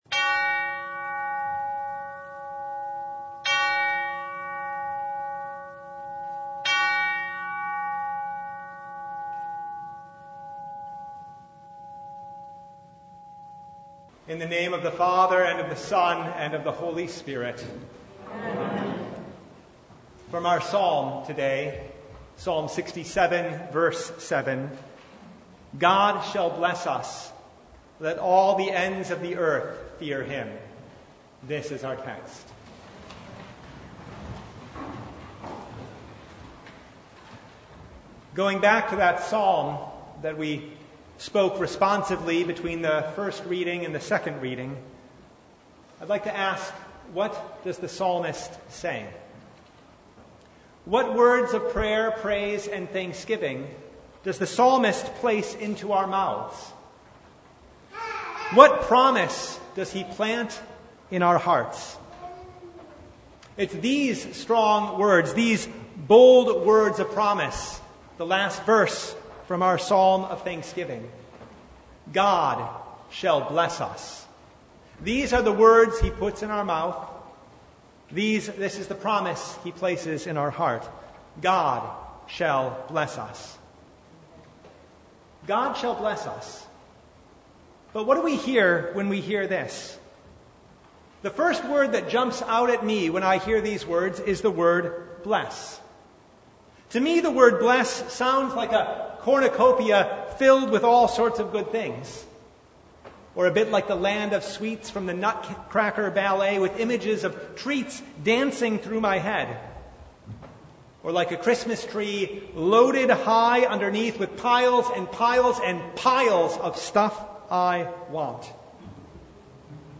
Psalm 67:1-7 Service Type: Thanksgiving Eve “God shall bless us”